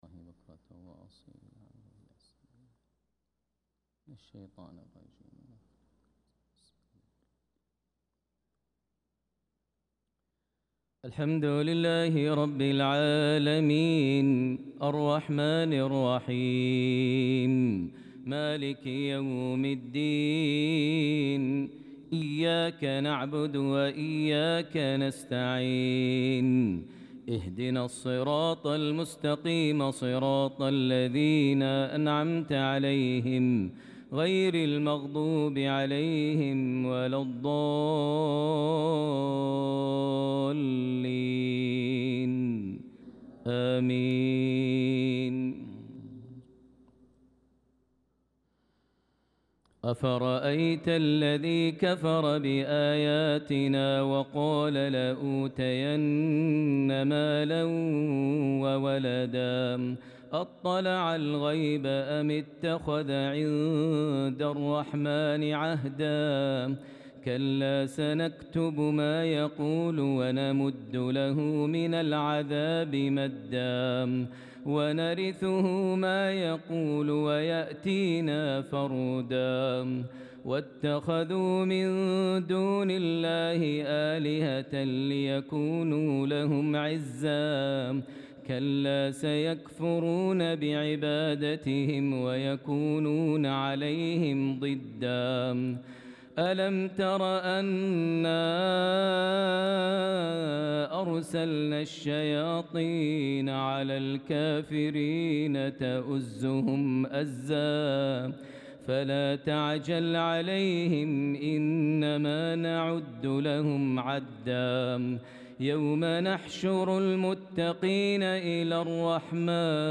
صلاة العشاء للقارئ ماهر المعيقلي 24 ذو القعدة 1445 هـ
تِلَاوَات الْحَرَمَيْن .